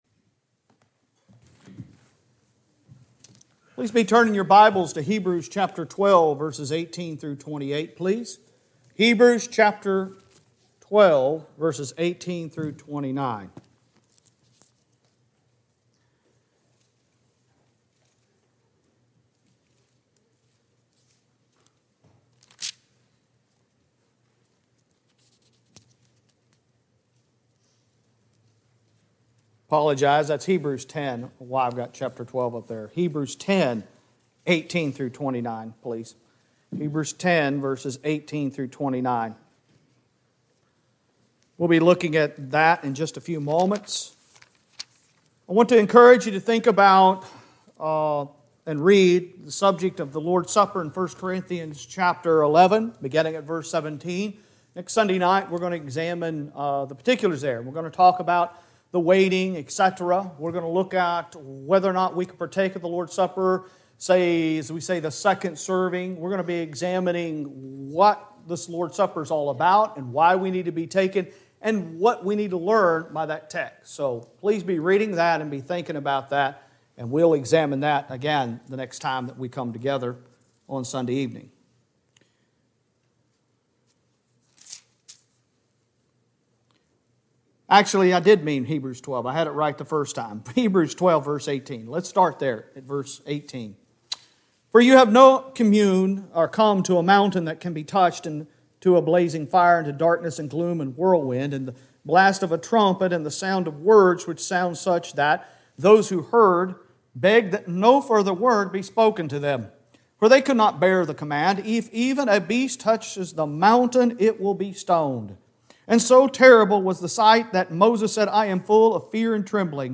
Sun PM sermon 03 July 2022 making a gracious response